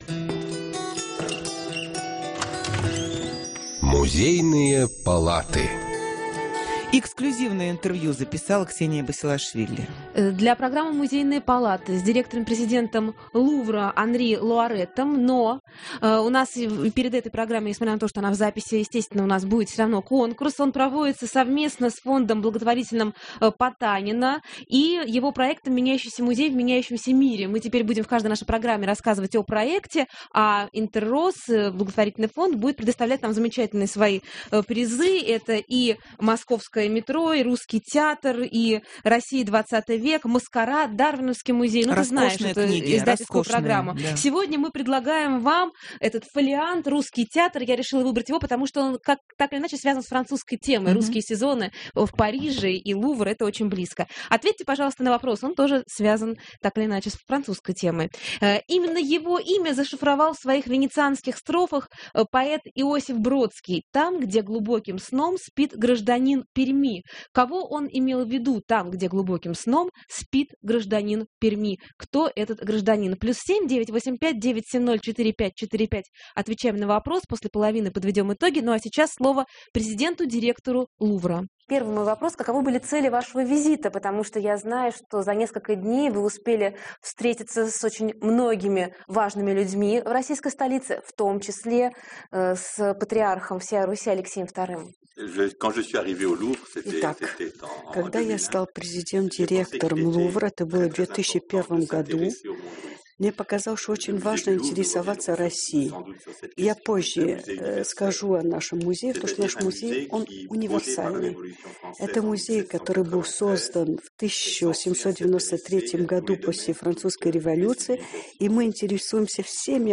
Эксклюзивное интервью